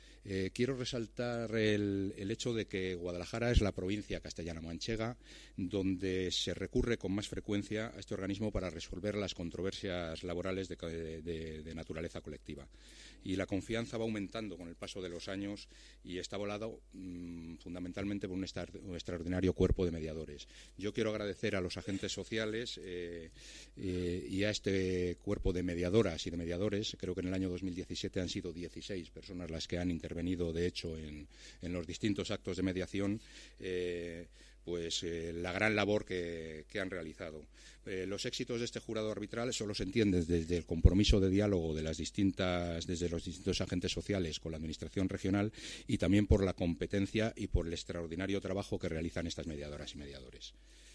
El director provincial de Economía, Empresas y Empleo, Santiago Baeza, habla del funcionamiento del Jurado Arbitral Laboral.